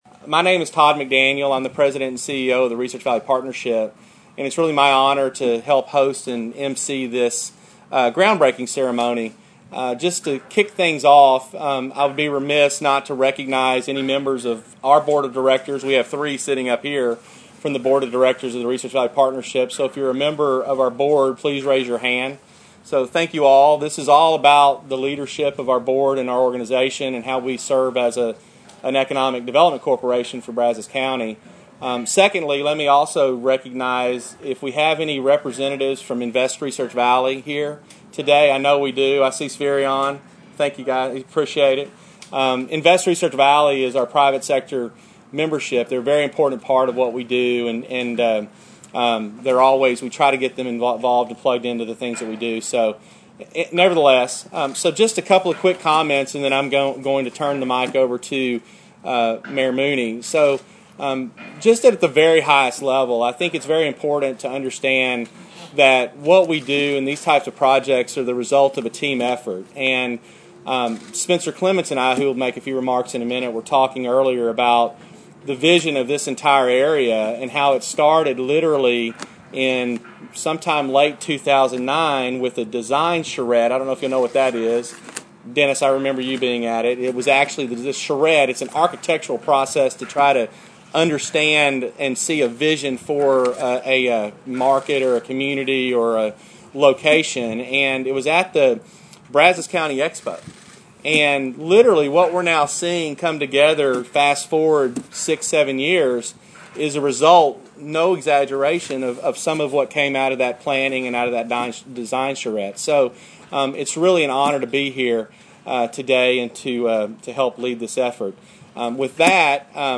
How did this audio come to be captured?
Click below for comments from the groundbreaking program.